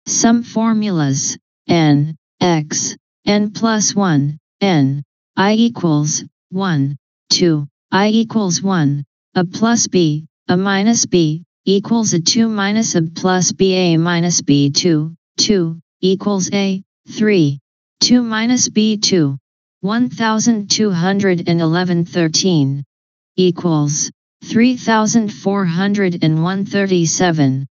The audio renderings presented as as part of the talk are